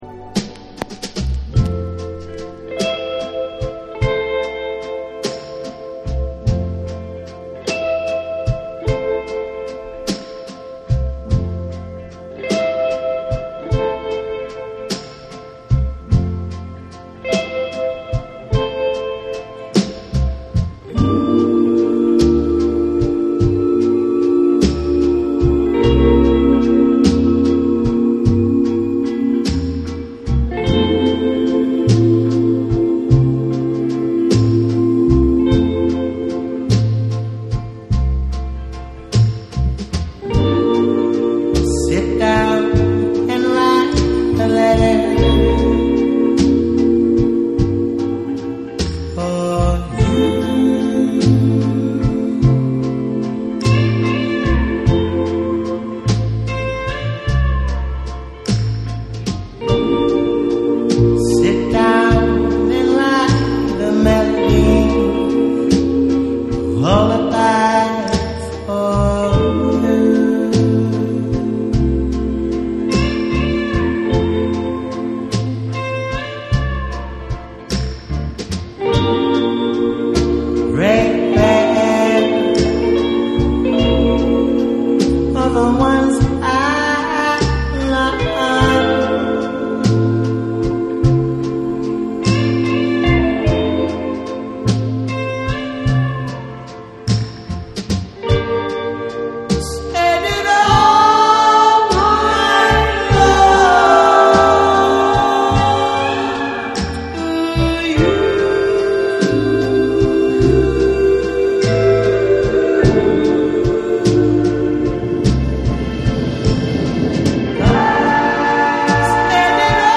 SOUL & FUNK & JAZZ & etc / MIX CD / NEW RELEASE(新譜)